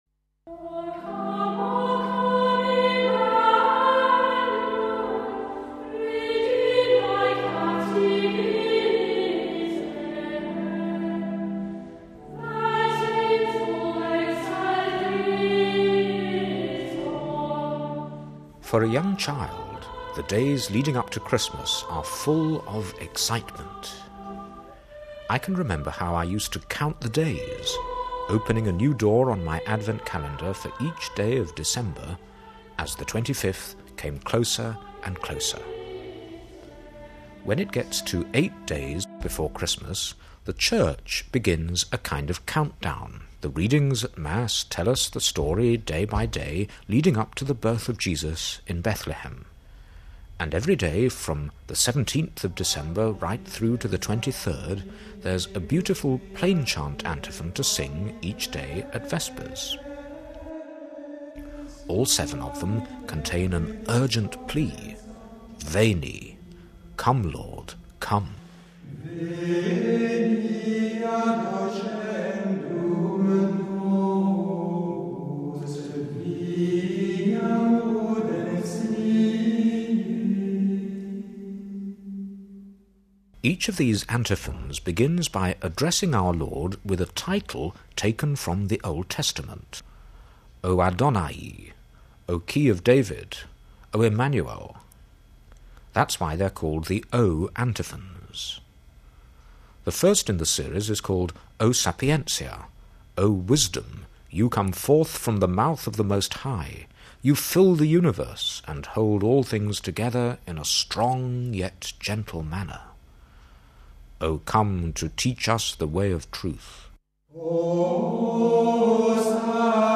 Every day, from the 17th to the 23rd of December , there's a beautiful plainchant antiphon to sing at Vespers.all seven contain an urgent plea, Veni, come, Lord , come. All seven are full of wonderful images expressing the hope of God's people , waiting for the birth of the Saviour.